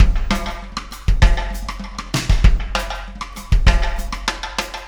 Pulsar Beat 24.wav